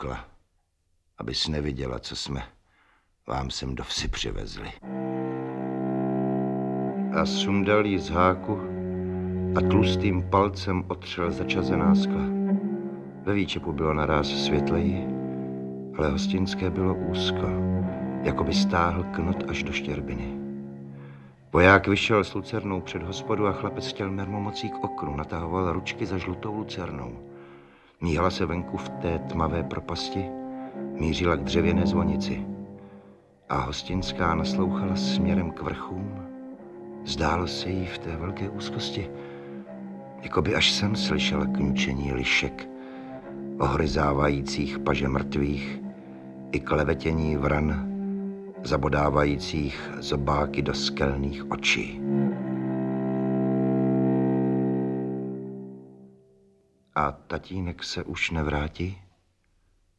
Audiobook
Audiobooks » Short Stories, Classic Works